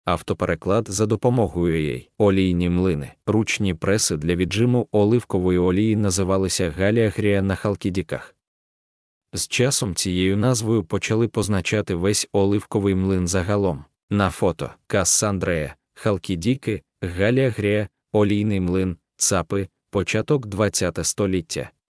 Аудіогід